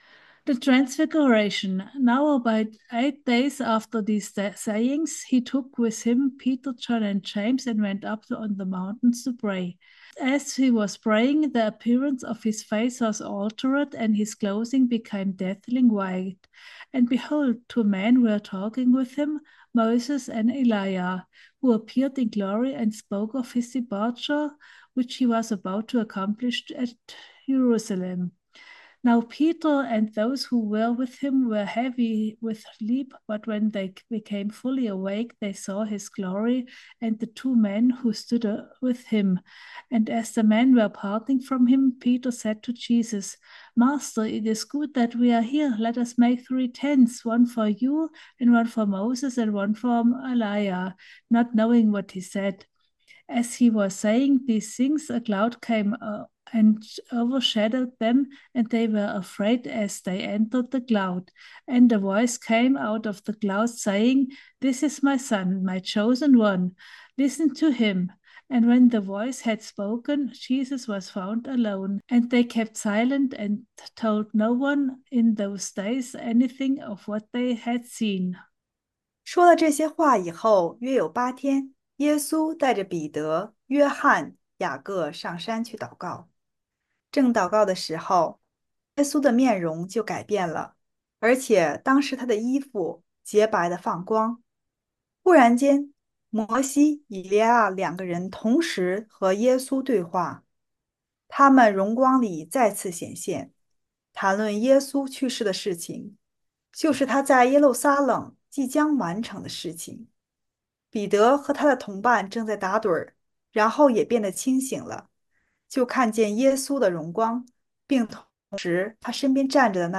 Bibelstudy Podcast
We will use a relaxed and enjoyable learning style to bring you into a new world of study.